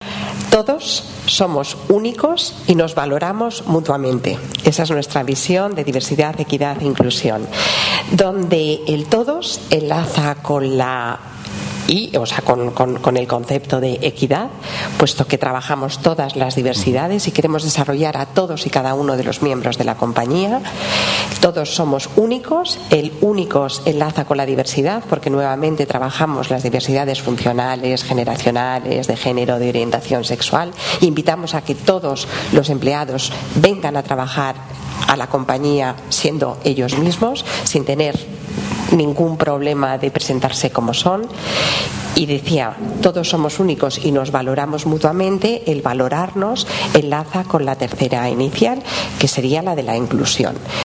Mesa redonda por la diversidad transversal